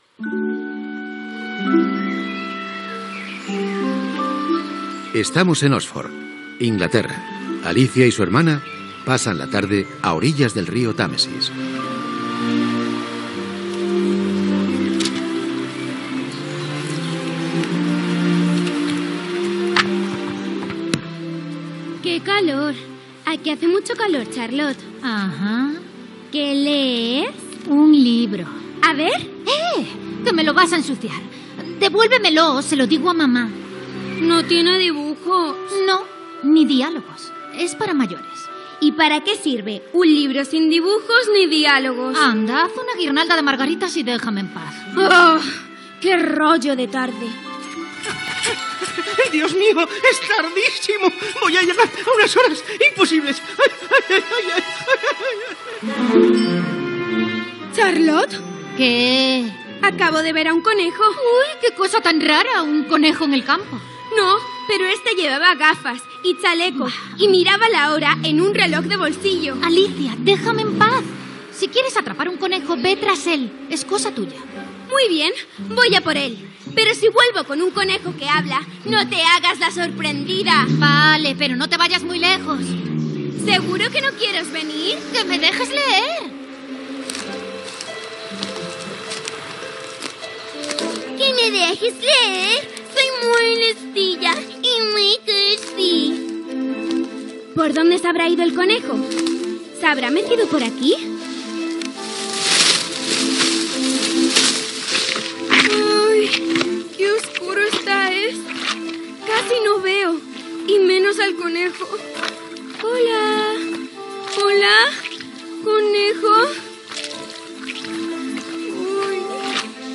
Ficción sonora
Primers minuts de l'adaptació radiofònica, careta del programa, represa de la història i participació del públic assistent.
Ficció